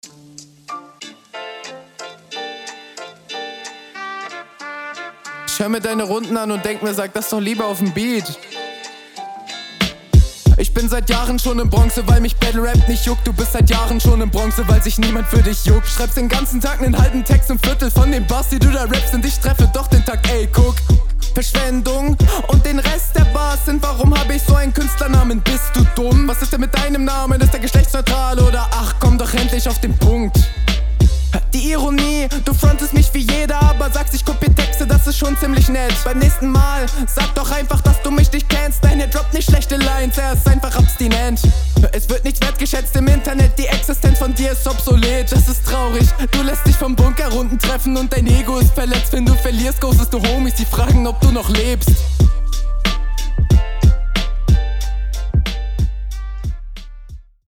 Coole Attitüde, cooler Style, cool gerappt.